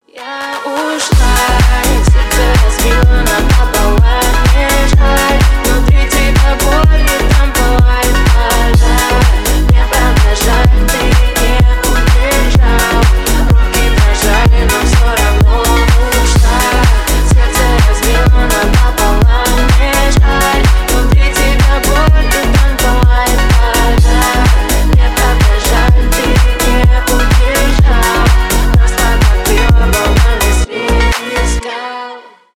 dance pop
клубные
club house